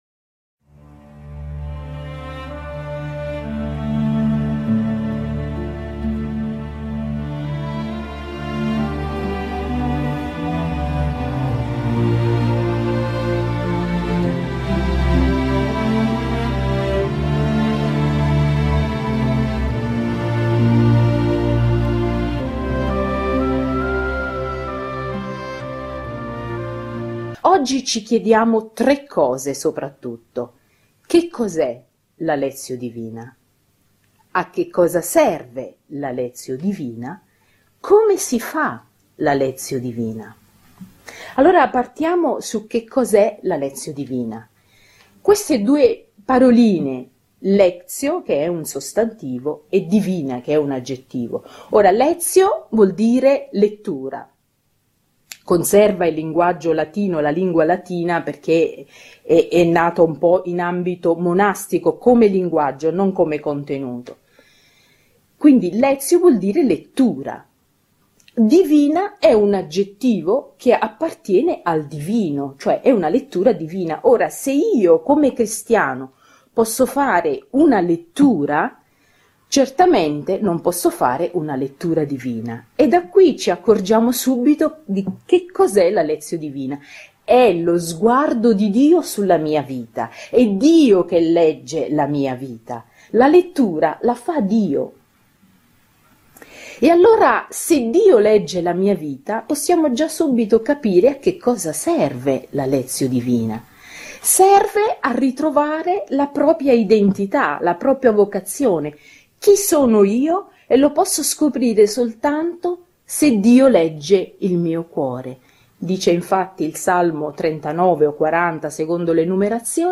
2 domenica di quaresima 1 domenica di quaresima Qui ! oppure Lectio divina, Parola di Dio meditata Benvenuti a Lectio Divina, il podcast che ti invita a riflettere sulla Parola di Dio che ascoltiamo di domenica in domenica. Qui non troverai prediche, ma un’opportunità di approfondire come la Parola di Dio possa influenzare la tua vita e la tua esistenza attraverso una lettura meditativa.